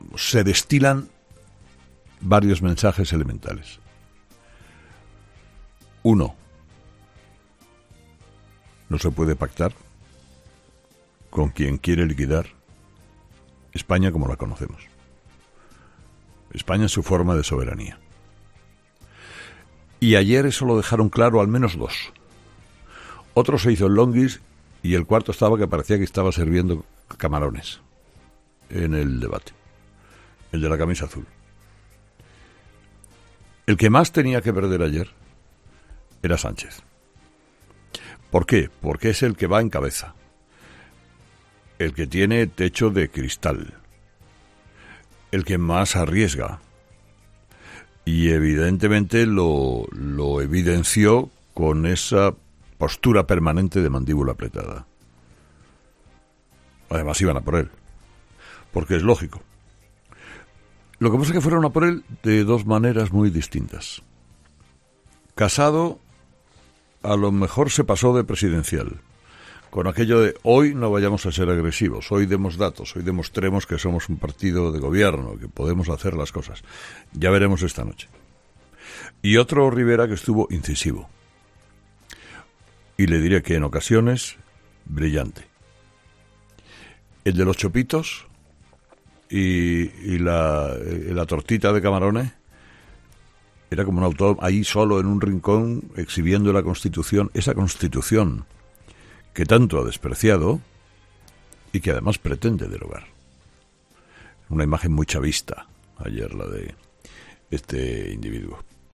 Esto mismo ha destacado Carlos Herrera en su monólogo de las 6 de la mañana.